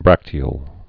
(brăktē-ōl)